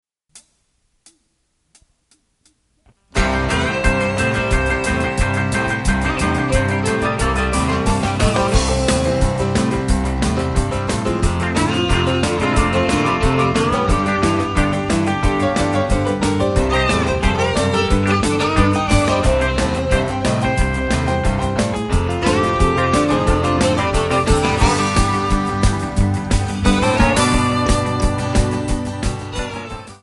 MPEG 1 Layer 3 (Stereo)
Backing track Karaoke
Country, Musical/Film/TV, 1980s